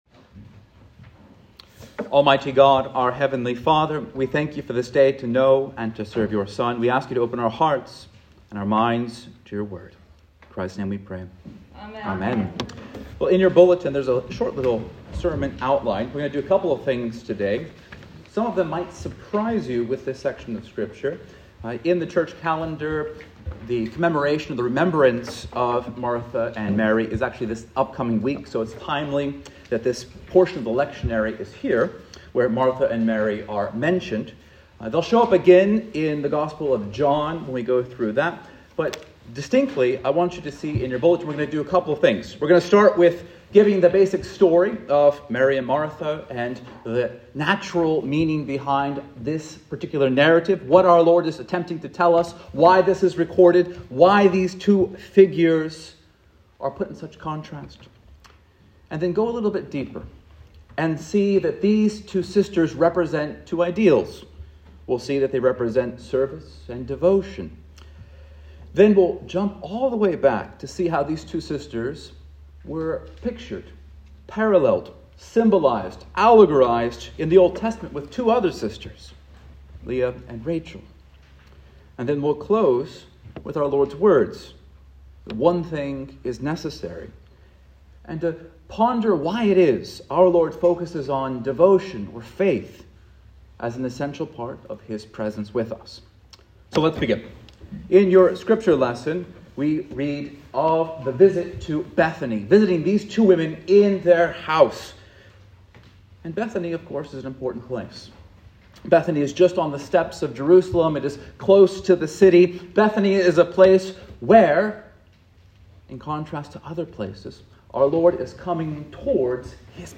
Sermon Podcast: Wisdom and Prudence in a Rushed World (St. Luke 10:38–42)